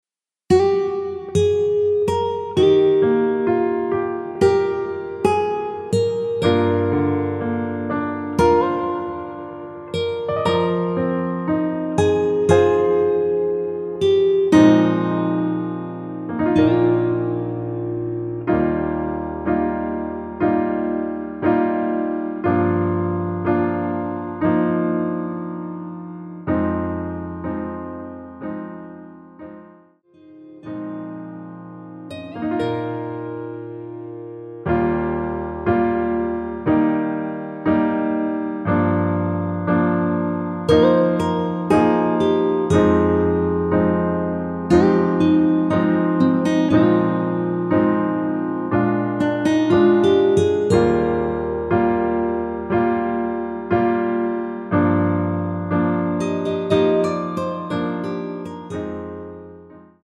MR입니다.
Eb
앞부분30초, 뒷부분30초씩 편집해서 올려 드리고 있습니다.
중간에 음이 끈어지고 다시 나오는 이유는